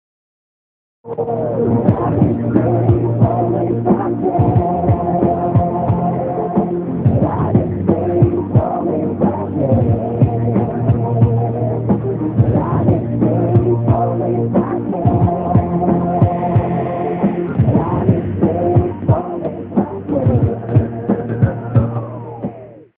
Во какое качество звука..!